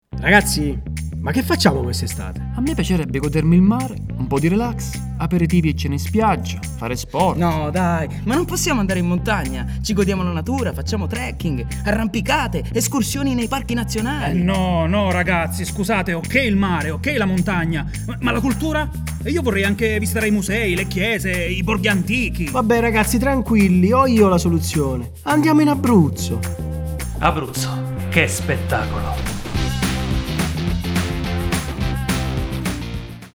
SPOT RADIO
spot-regione-abruzzo-.mp3